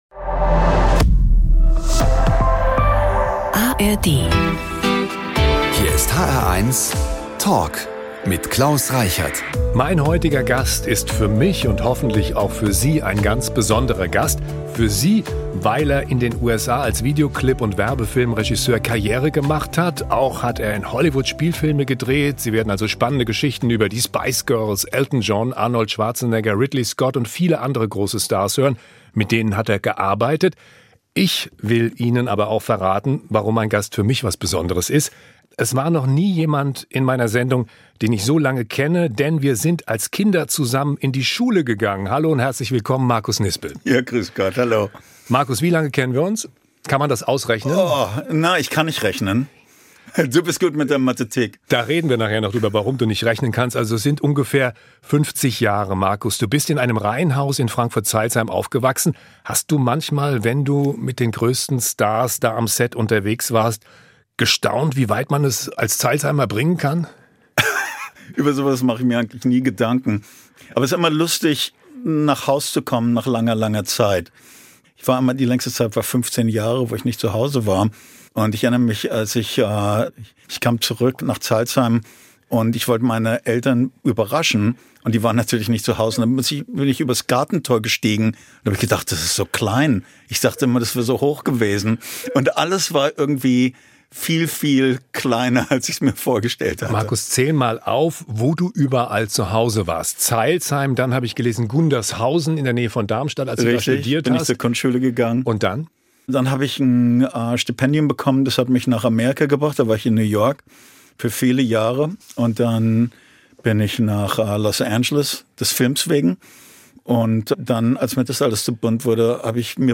Überraschende Einblicke und spannende Ansichten: Die hr1-Moderatoren im sehr persönlichen Gespräch mit Prominenten.